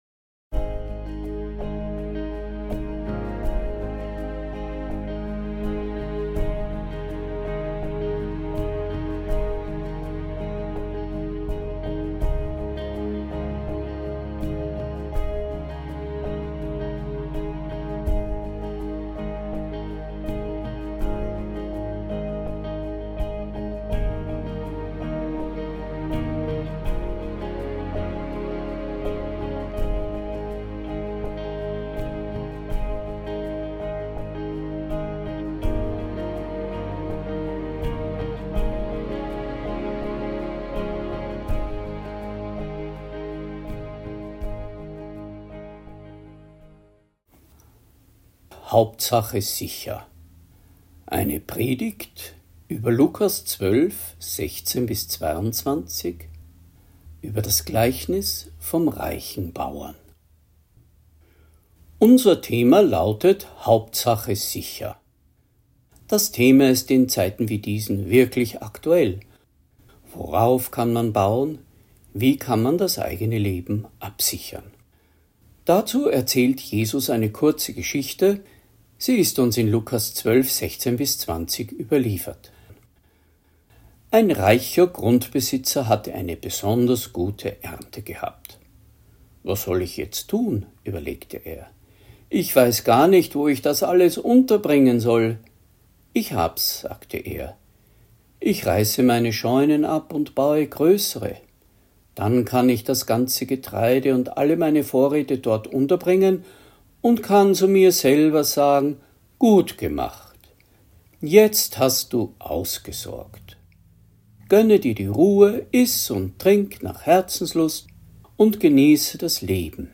Predigt | NT03 Lukas 12,16-20 Der reiche Kornbauer